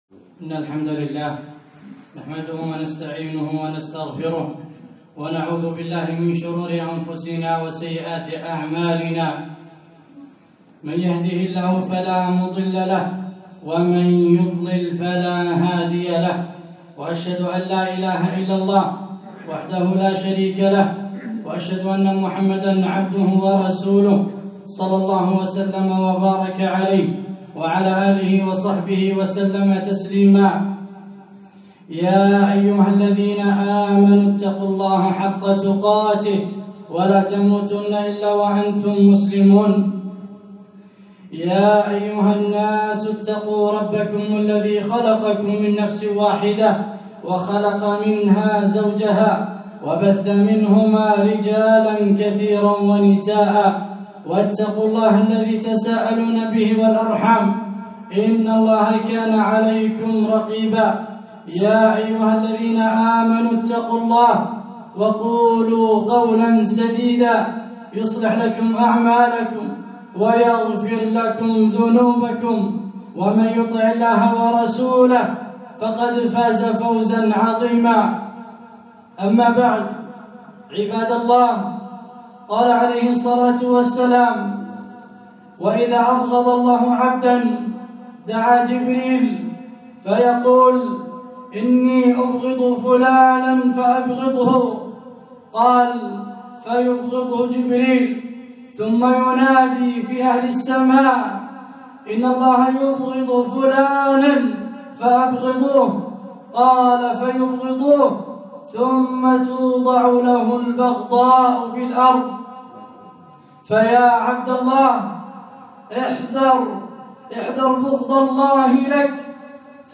خطبة - غضب الله وبغضه